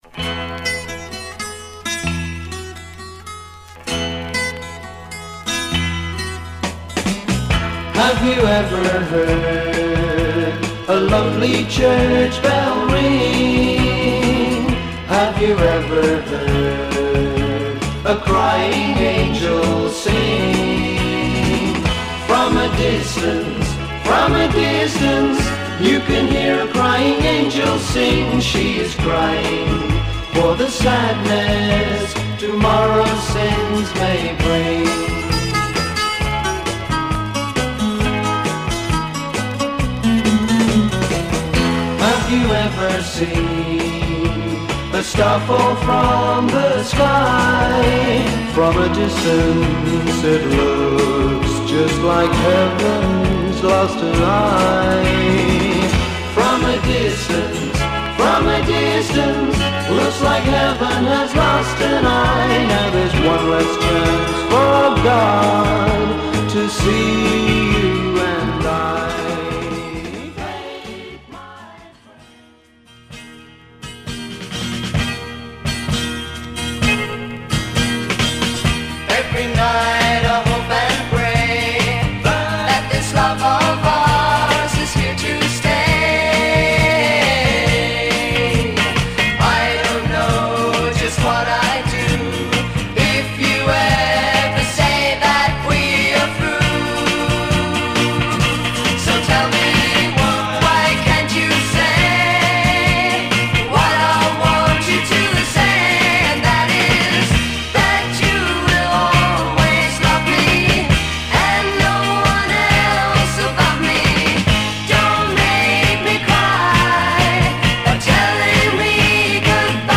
Stereo/mono Mono
Rock